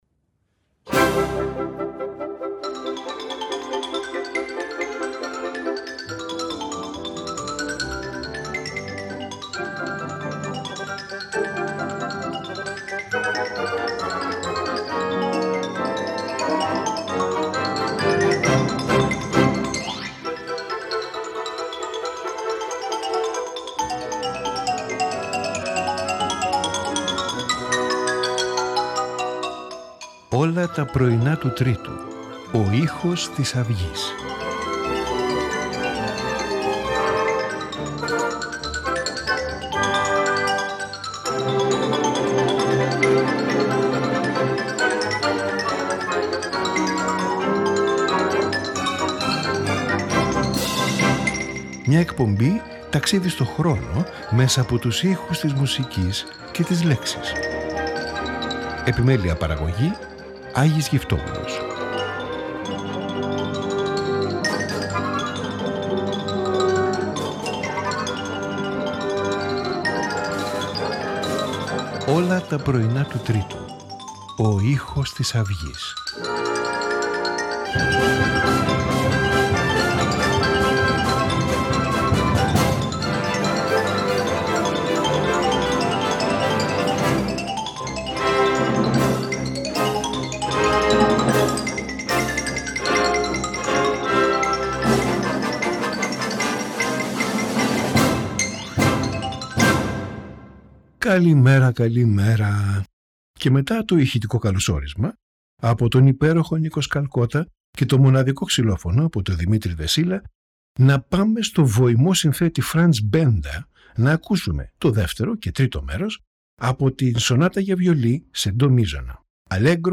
Franz Benda – Violin Sonata in C major, LIII:2J.S. Bach – French Suite No. 1 in D minor, BWV 812Aram Khachaturian – Flute Concerto Jean-Philippe Rameau – Rigaudon and Le rappel des oiseaux (The Call of the Birds)